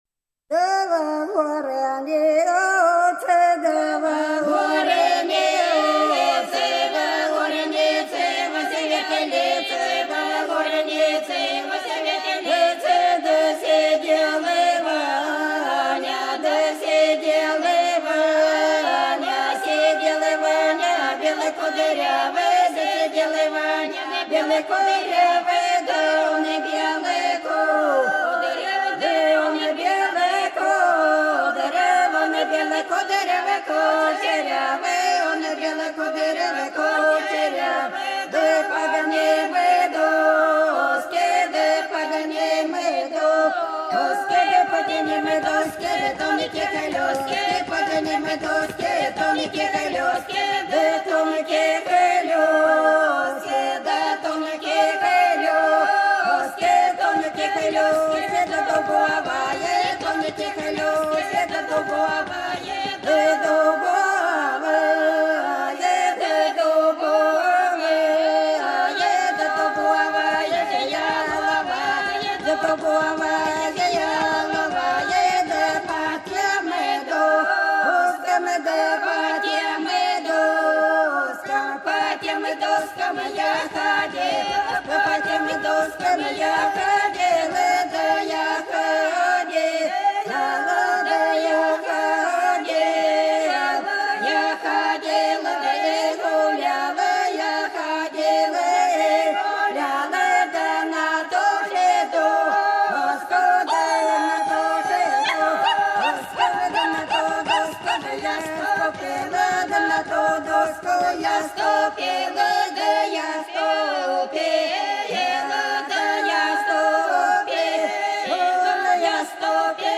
Долина была широкая (Поют народные исполнители села Нижняя Покровка Белгородской области) Во горнице, во светлице - плясовая